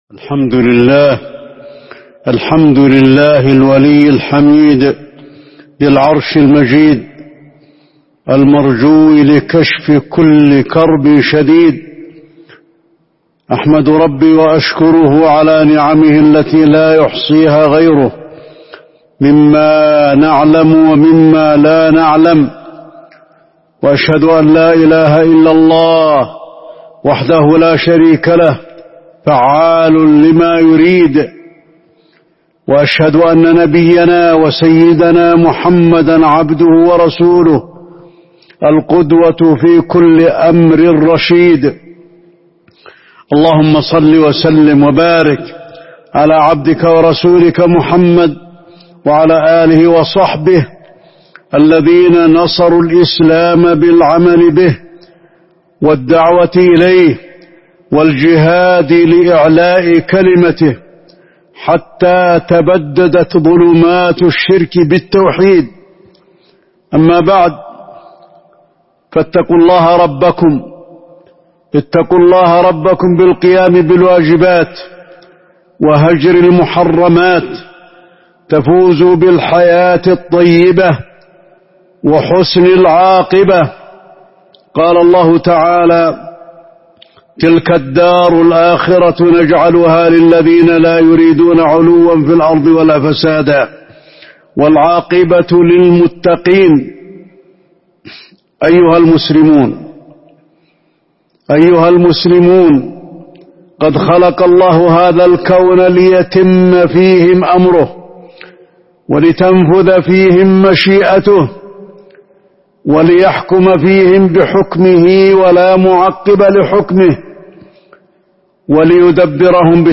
تاريخ النشر ١٦ جمادى الآخرة ١٤٤٢ هـ المكان: المسجد النبوي الشيخ: فضيلة الشيخ د. علي بن عبدالرحمن الحذيفي فضيلة الشيخ د. علي بن عبدالرحمن الحذيفي شكر النِعم